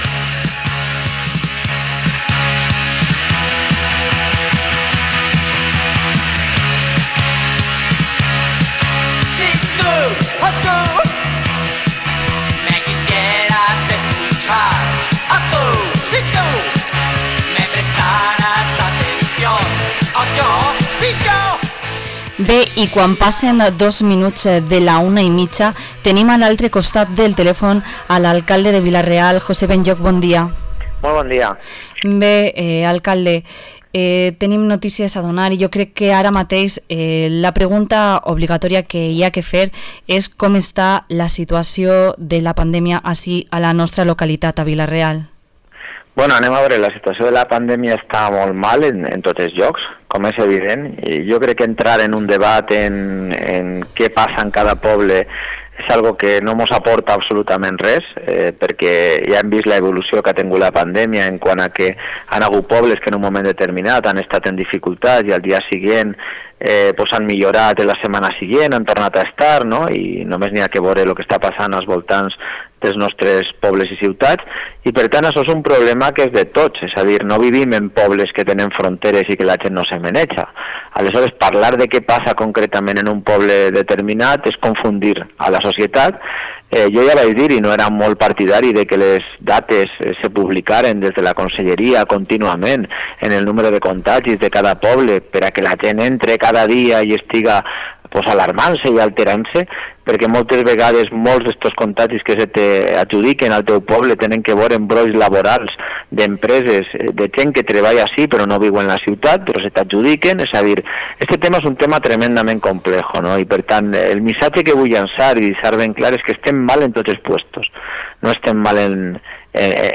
Entrevista al alcalde de Vila-real, José Benlloch